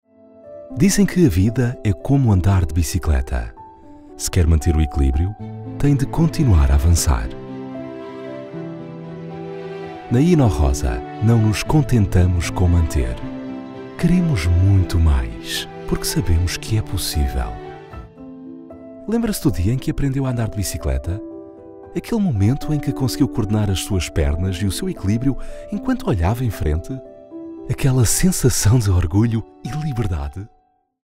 The Portuguese voiceover, friendly and impactful!
Narration